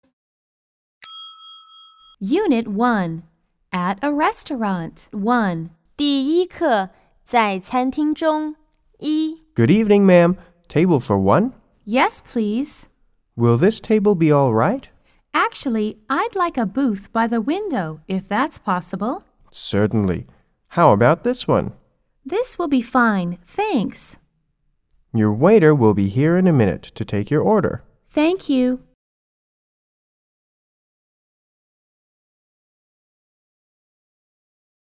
W= Waiter P= Patron